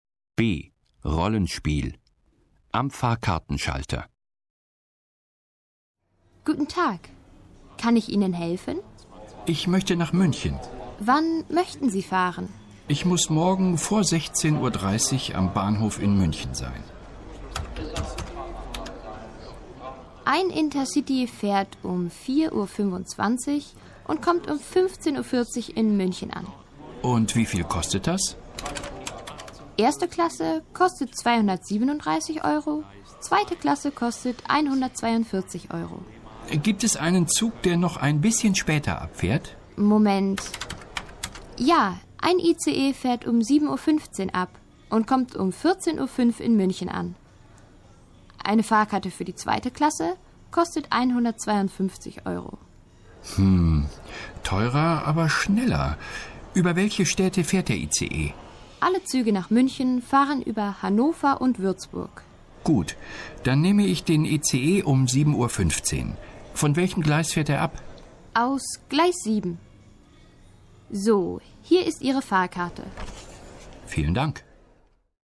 A. Dialog aus dem Text: Eine Bahnfahrt online buchen (1146.0K)